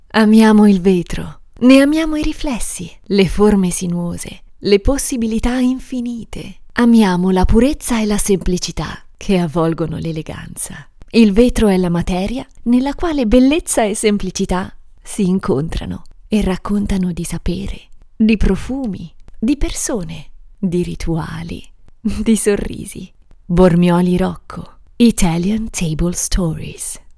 Clear voice, fresh, charming and persuading. Home studio recording service.
Sprechprobe: Industrie (Muttersprache):